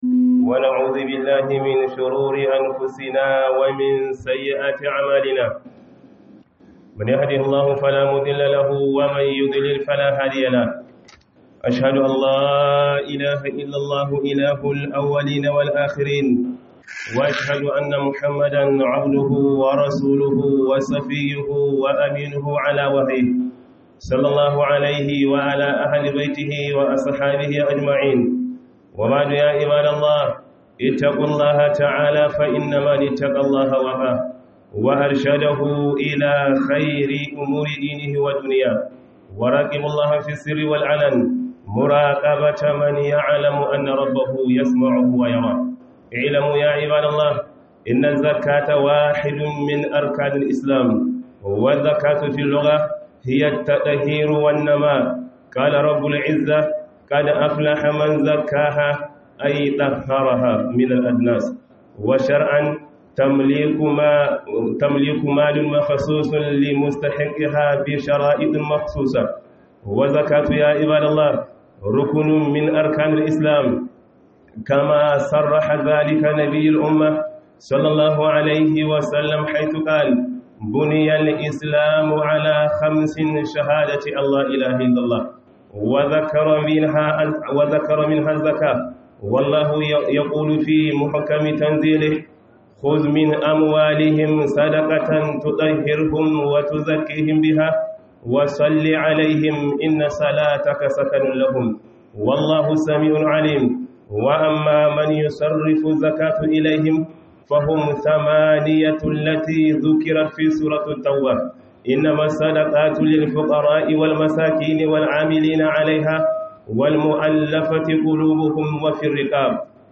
Khuduba - Mu bayar da zakka